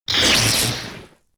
repair5.wav